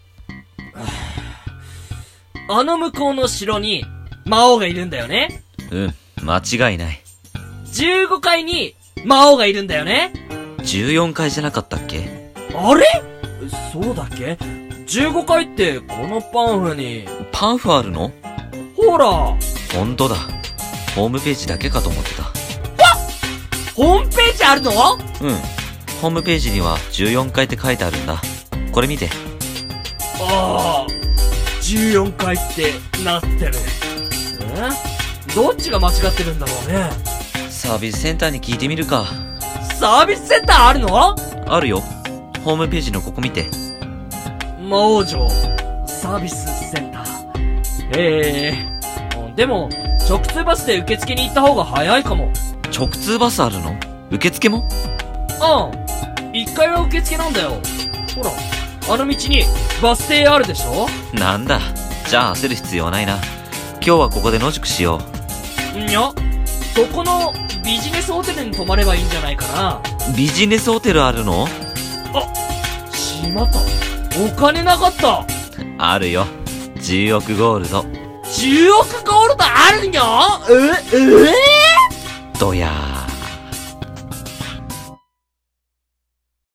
ギャグ声劇 「いざ！魔王城へ！」